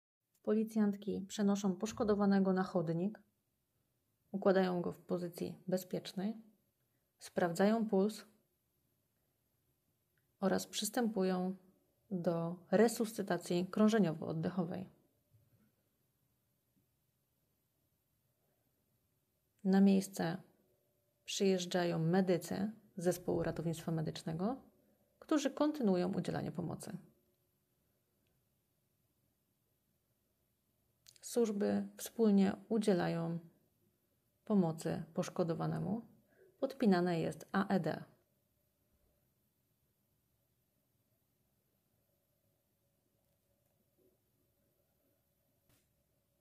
Nagranie audio deskrypcja nagrania.mp3.mp3